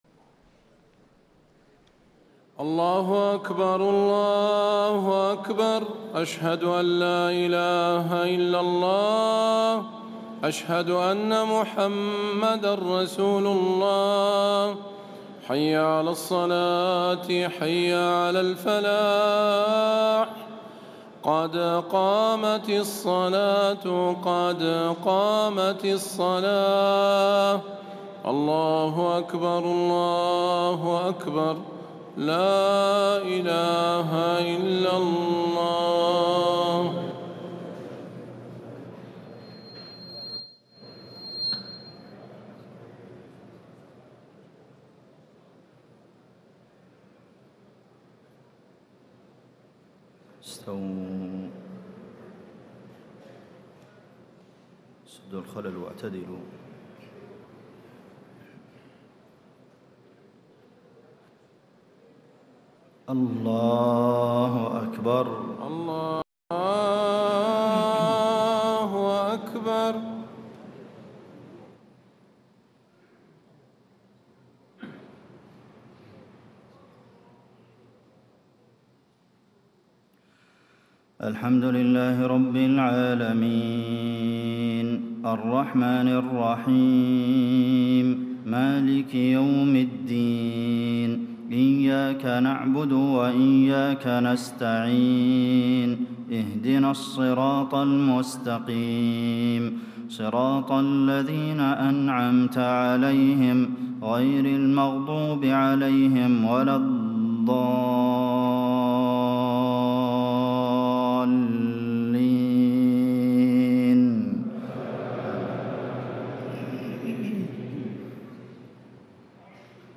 صلاة العشاء 1 شعبان 1437هـ من سورة الحج 8-17 > 1437 🕌 > الفروض - تلاوات الحرمين